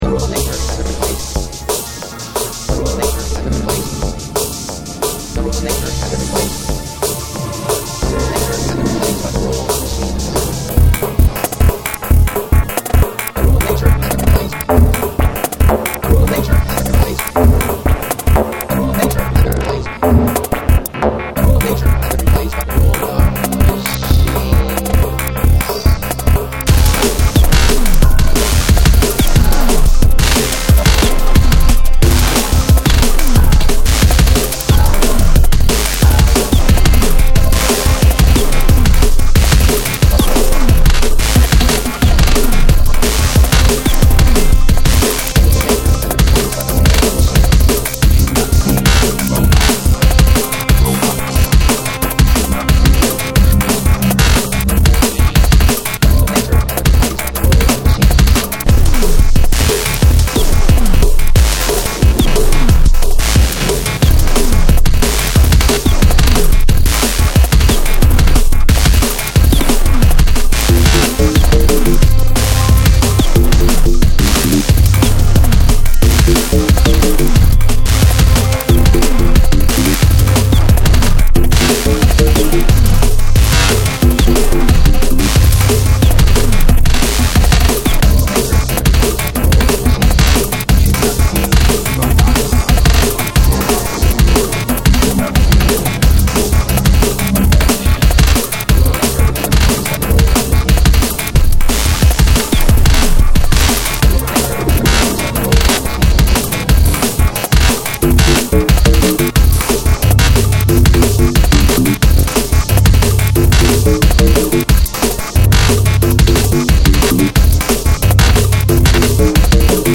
dance/electronic
Techno
Drum & bass
IDM